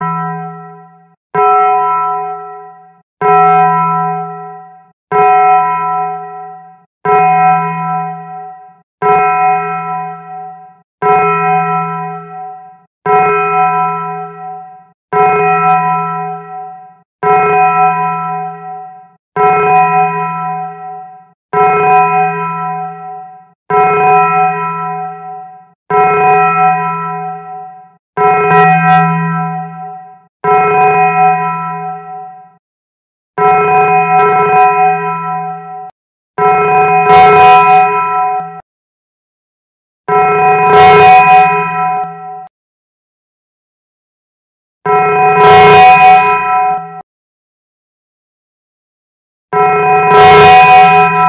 Multiple Taco Bells Sound Effect Free Download